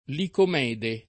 Licomede [ likom $ de ]